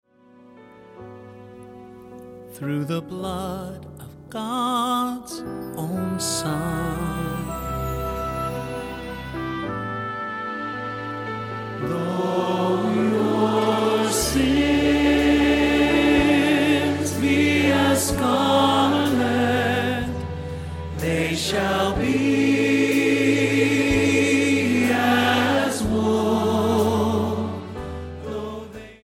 STYLE: Choral